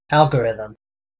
Ääntäminen
US Tuntematon aksentti: IPA : /ˈælɡəɹɪðm/